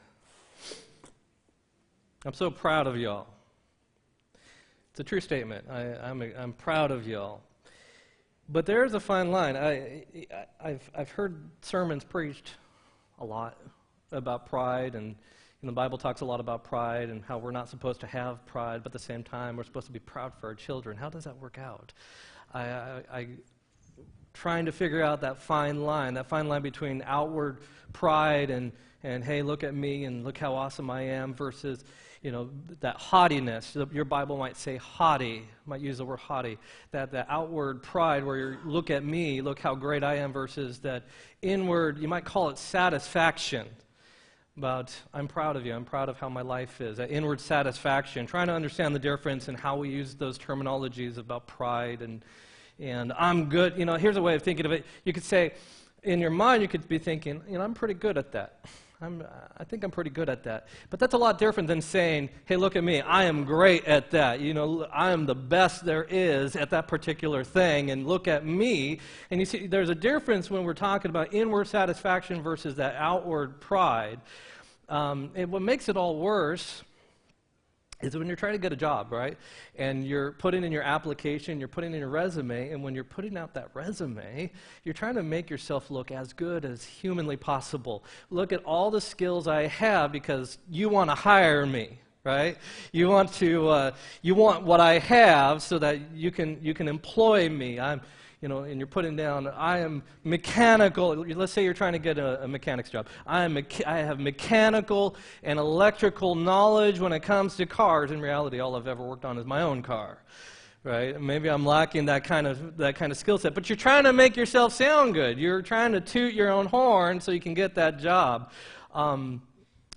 10-7-17 sermon
10-7-17-sermon.m4a